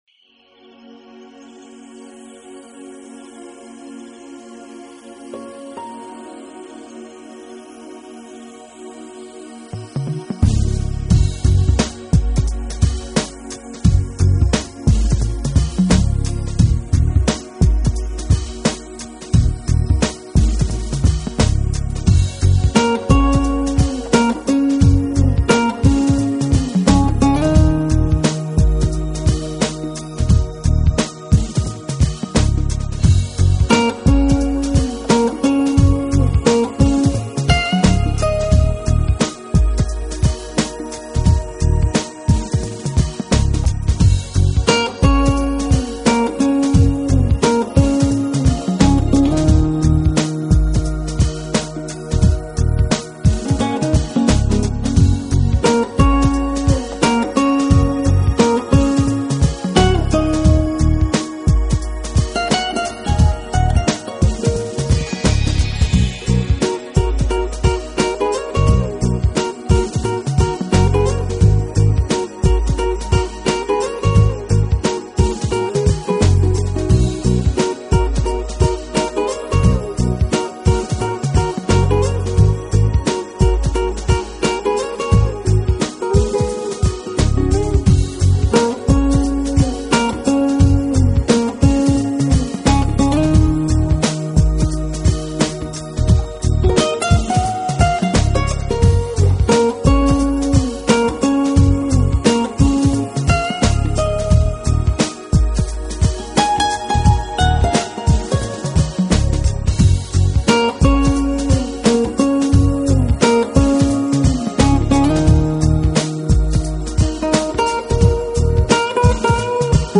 的音乐风格是定位在“Contemporary Instrumental”（现代器乐），这种音乐风格虽
作品中融入了大量现代电子舞曲元素
前面的主题如晨曲般优美，迎着朝阳，闭上眼睛深呼吸……噢！--　清新。